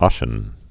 (ŏshən, ŏsē-ən)